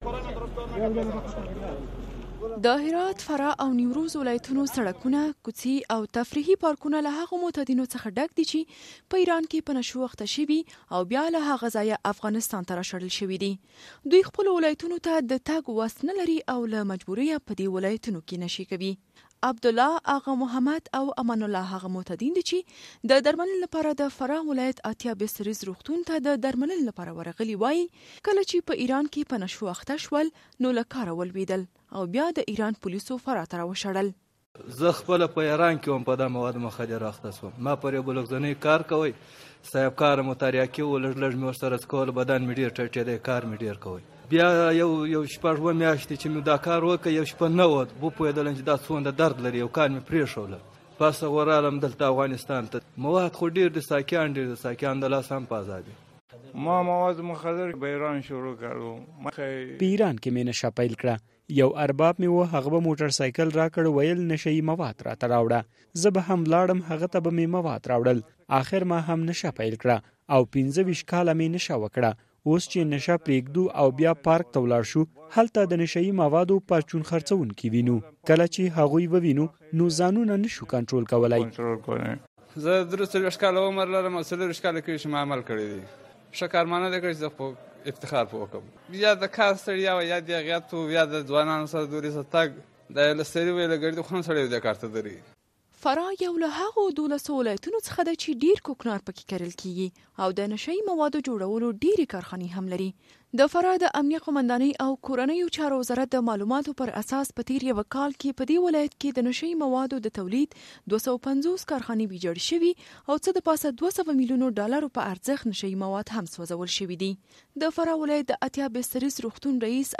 د فراه راپور